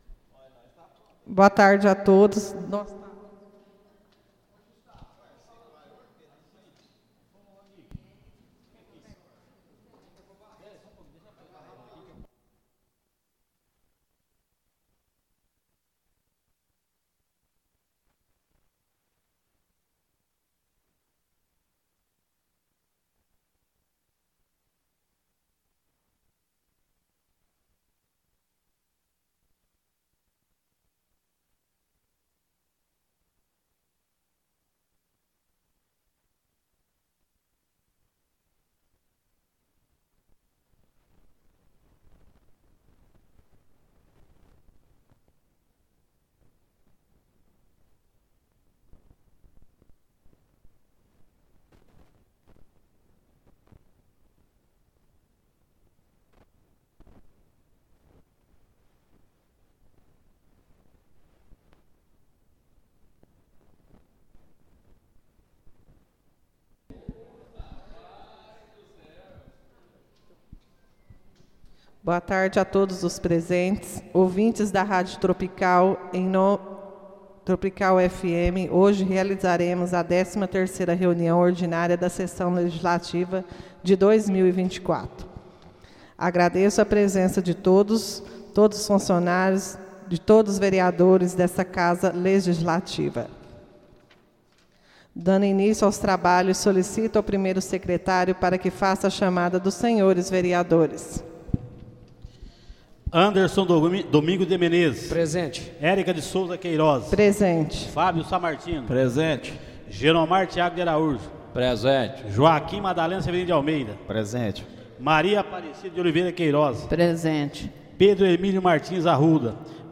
Áudio da 13.ª reunião ordinária de 2024, realizada no dia 19 de Agosto de 2024, na sala de sessões da Câmara Municipal de Carneirinho, Estado de Minas Gerais.